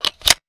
weapon_foley_pickup_11.wav